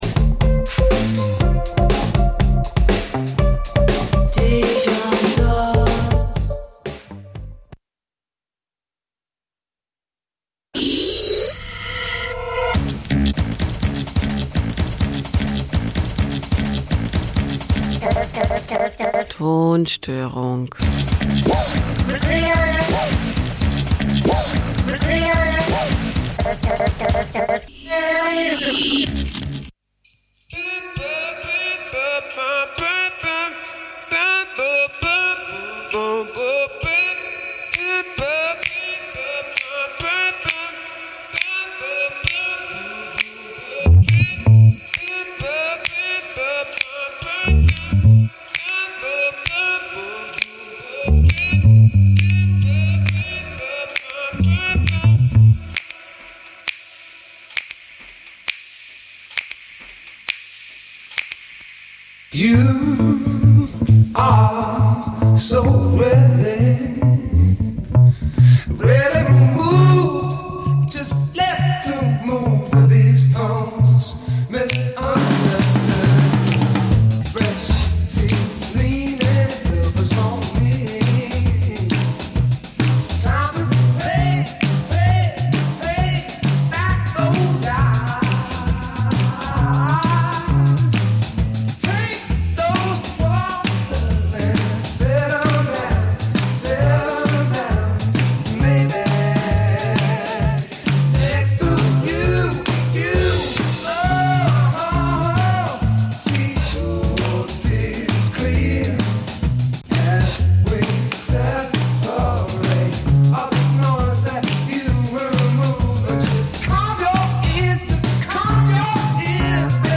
Abstrakte Soundwelten, nie geh�rte Stimmen und musikgewordene Filme f�r Dein Hirnkino, jenseits von Eurodance und Gitarrengeschrammel.
Das Spektrum der musikalischen Bandbreite reicht von EBM , Minimalelektronik, Wave ,Underground 80`s bis hin zu Electro ,Goth und Industriell.
Sendung für elektronische Musik Dein Browser kann kein HTML5-Audio.